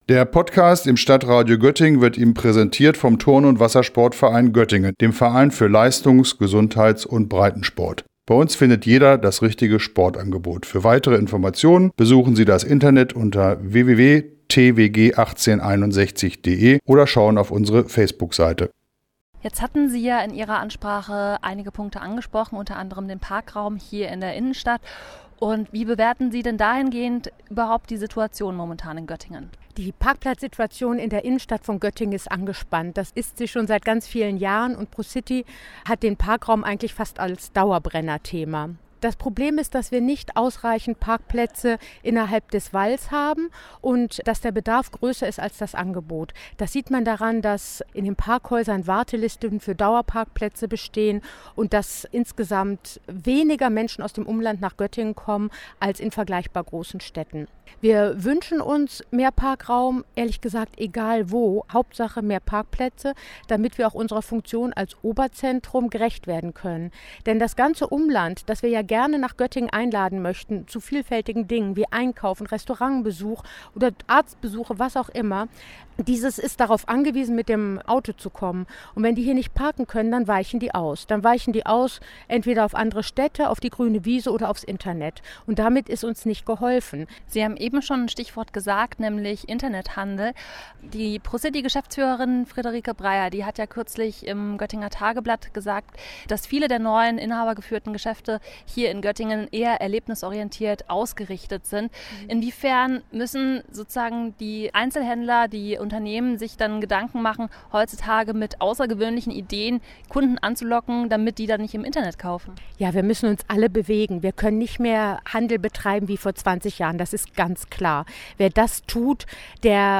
Der Göttinger Verein Pro City setzt sich mit diesen Themen auseinander. Gestern hat er seinen alljährlichen Sommerempfang abgehalten, an dem wieder hunderte Vertreter aus Politik, Wirtschaft und Co. teilnahmen.